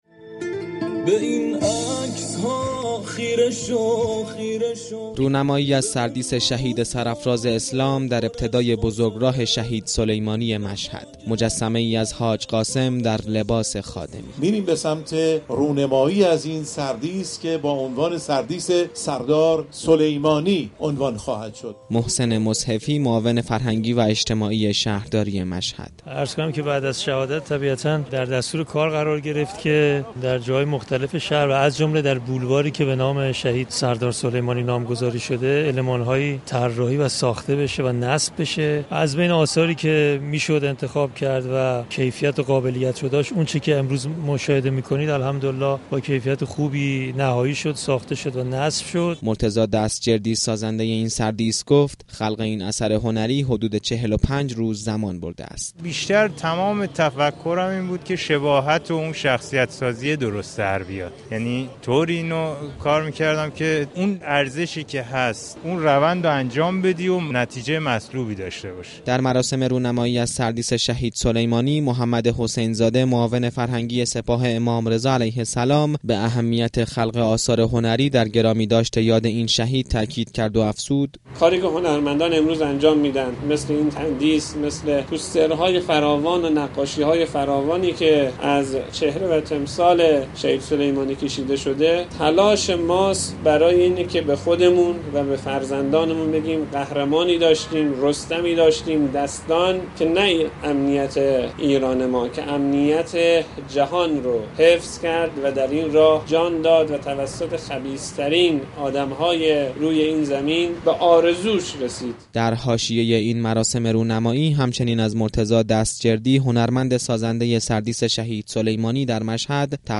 گزارش خبرنگار رادیو زیارت را از این مراسم بشنوید.